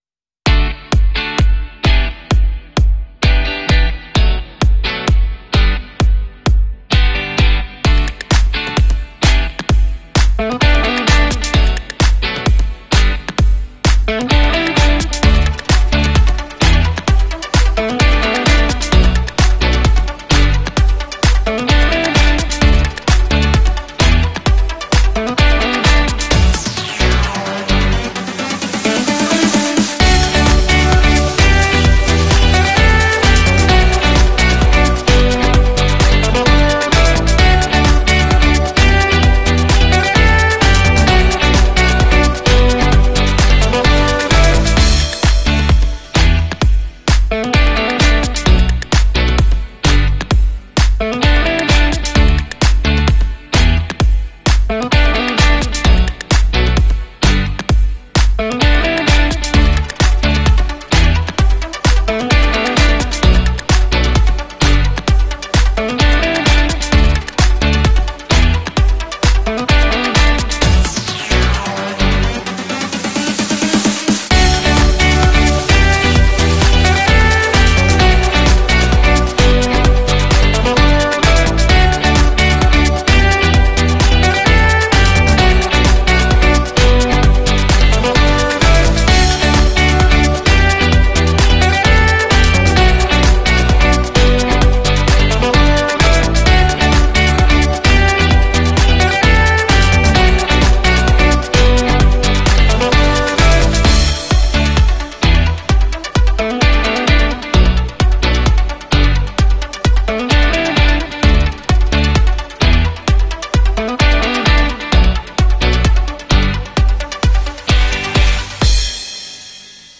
JY619 Cable Tacker & 1306C sound effects free download